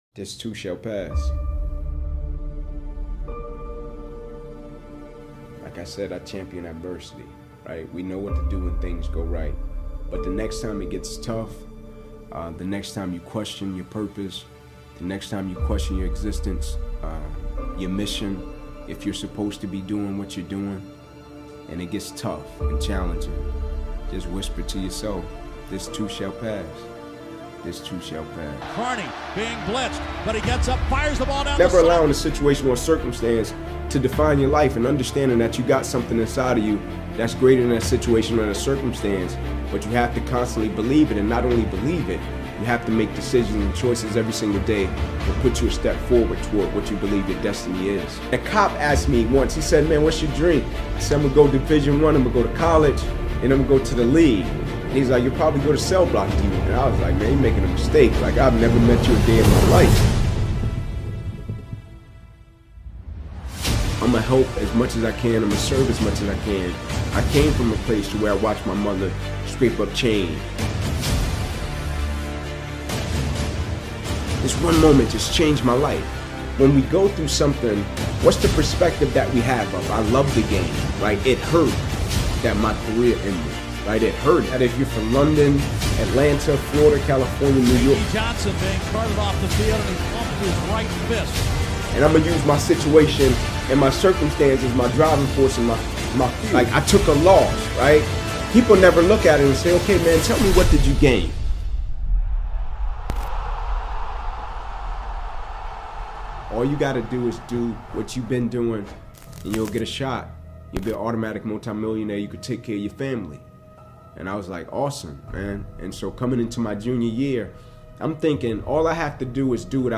Most Inspiring Speech on Persistence: Lessons for Bouncing Back Stronger